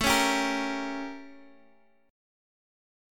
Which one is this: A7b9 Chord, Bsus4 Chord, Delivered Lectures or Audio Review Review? A7b9 Chord